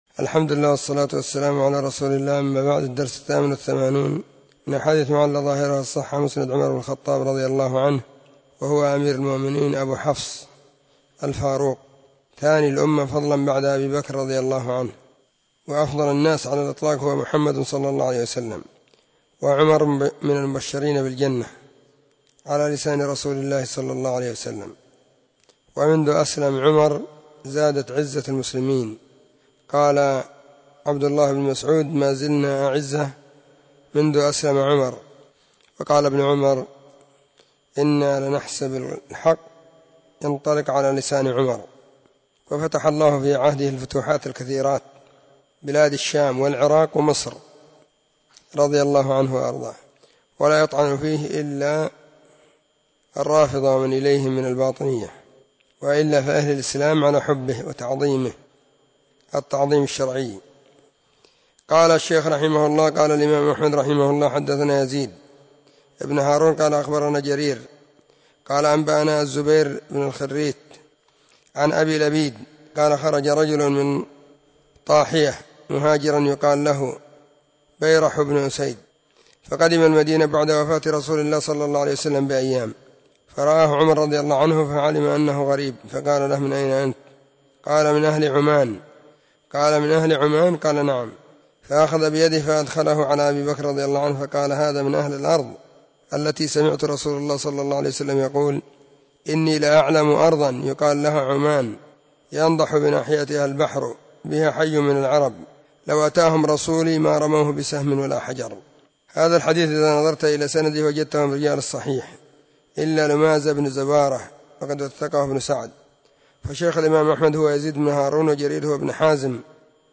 🕐 [كل يوم الاثنين – الدرس الثالث بين مغرب وعشاء]